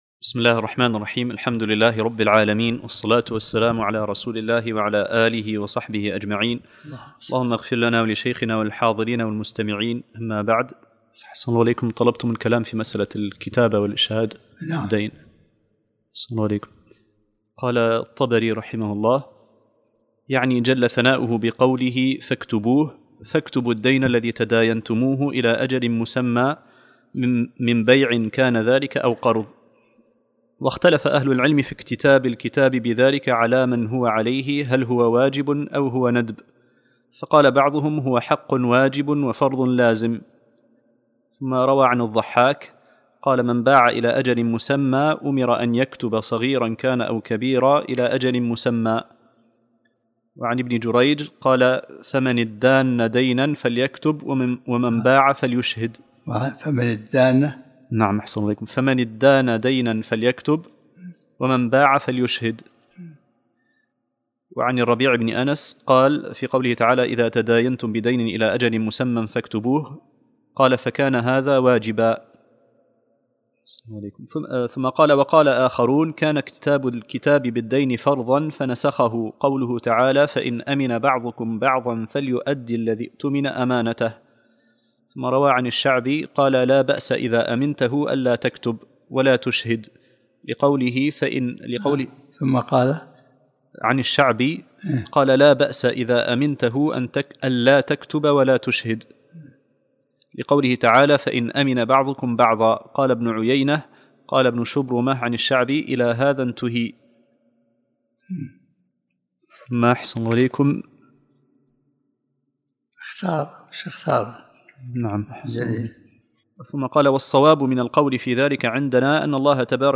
الدرس الرابع والعشرون من سورة البقرة